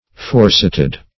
Forecited \Fore"cit`ed\, a. Cited or quoted before or above.